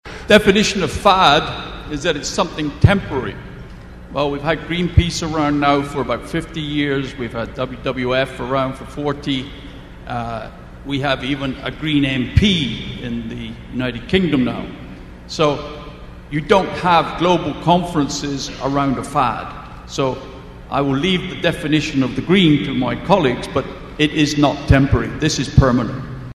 Great Debate at Alltech Symposium